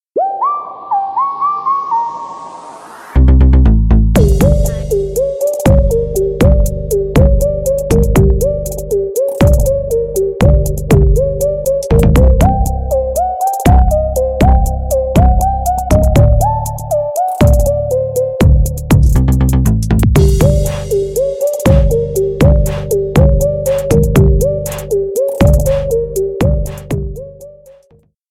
бесплатный рингтон в виде самого яркого фрагмента из песни
Электроника